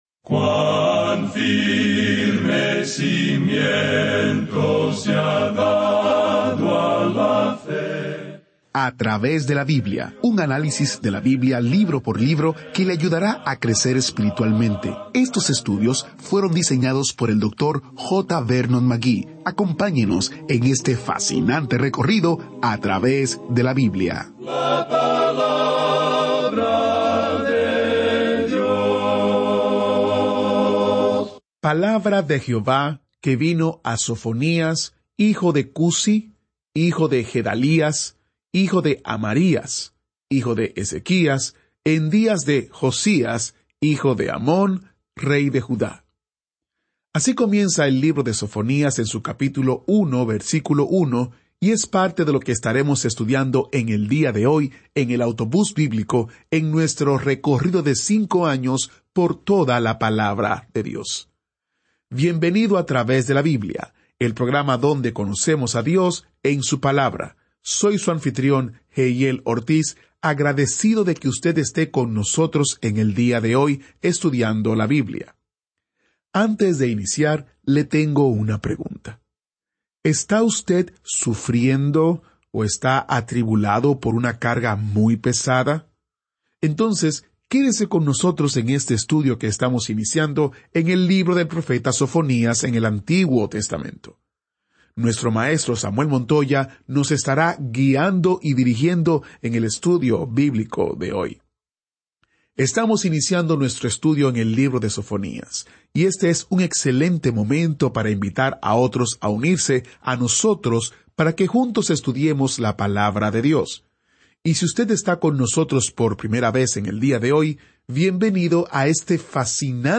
Este es un programa de radio diario de 30 minutos que sistemáticamente lleva al oyente a través de toda la Biblia.